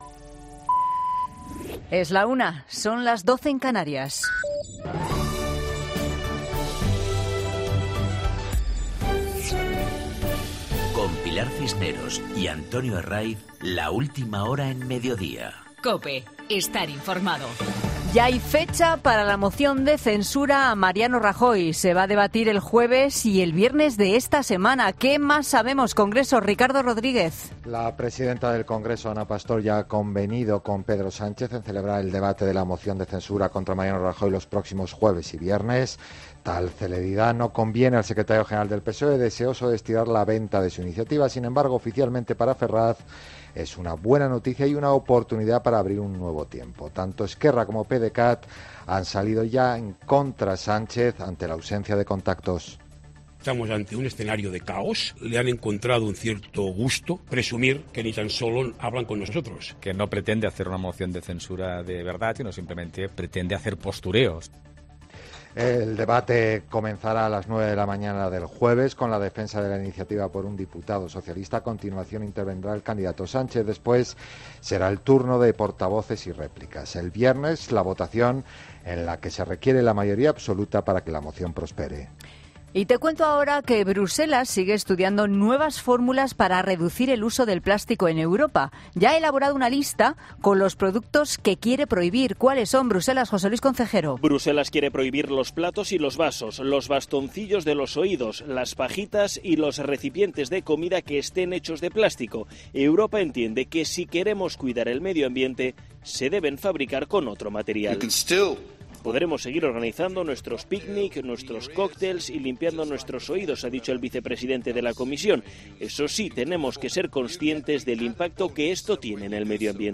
Boletín de noticias COPE del 28 de mayo de 2018 a las 13.00